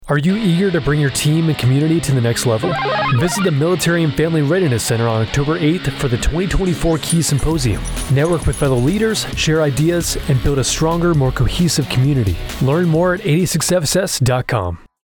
Radio Spot - 2024 Keys Symposium